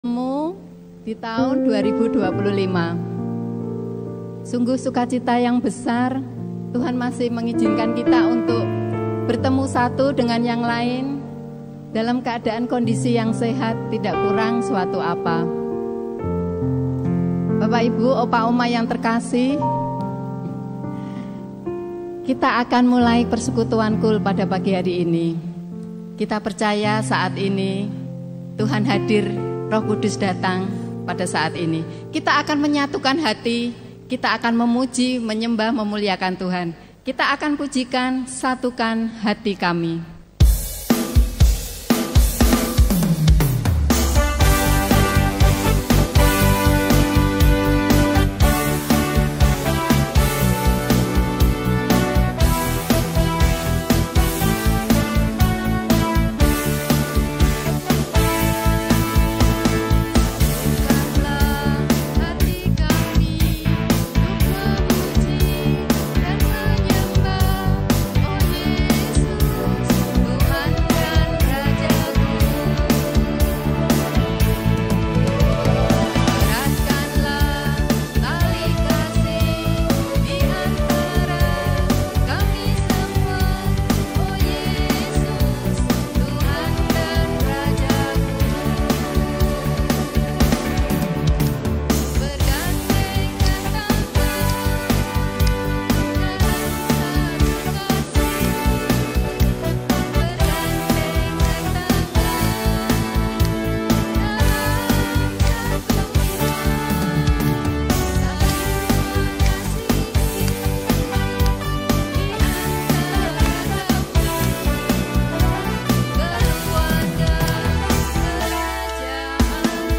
Persekutuan KUL Senior Persekutuan Komisi Usia Lanjut Senior GKI Peterongan Pemimpin
Onsite dan Online